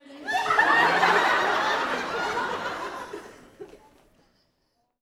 WoW-Sitcomify - Sitcomify is a World of Warcraft AddOn to automatically play random audience laugh tracks at appropriate moments.
Audience Laughing-00.wav